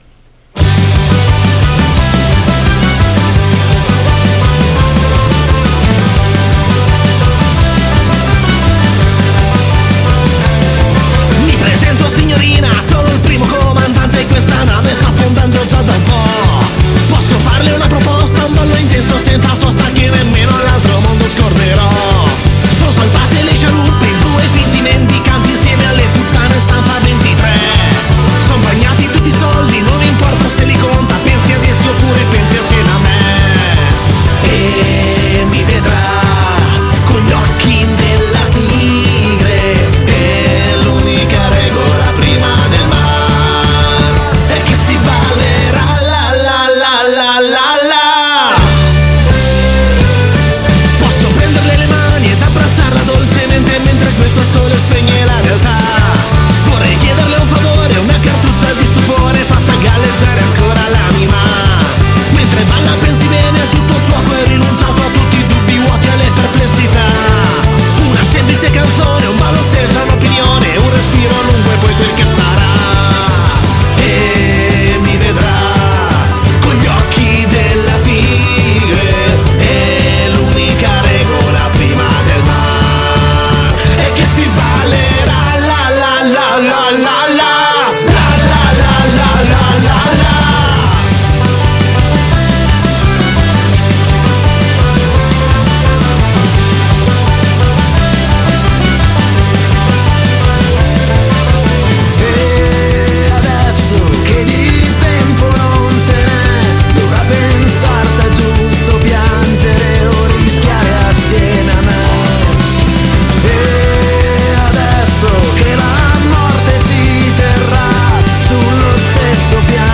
Intervista ai Lennon Kelly | 4-9-2023 | Radio Città Aperta